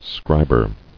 [scrib·er]